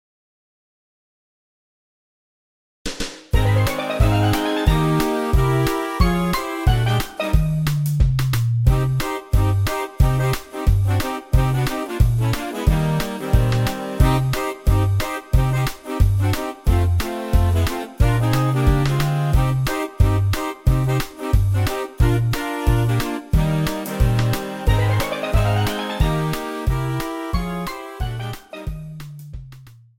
Backing